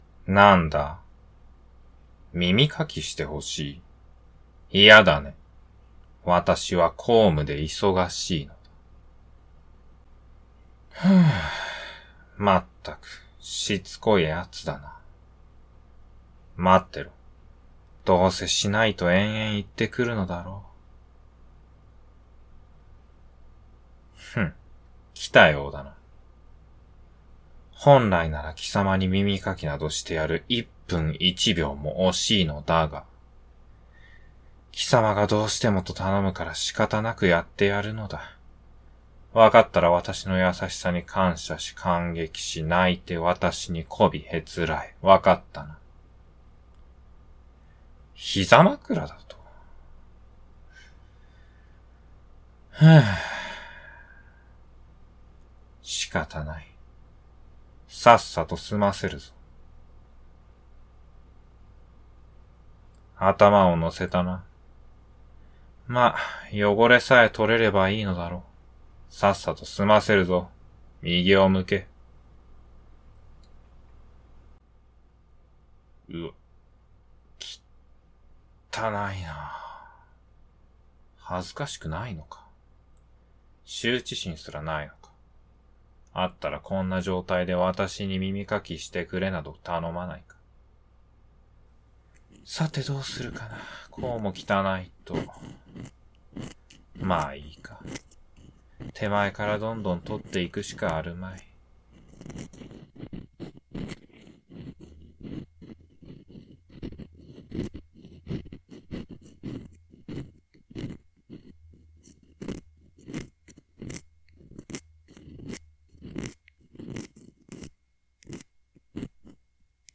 掏耳
ASMR
mimikaki_batou2.mp3